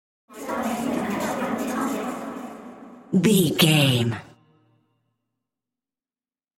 Middle Witch Chatting.
Sound Effects
Atonal
scary
ominous
eerie
creepy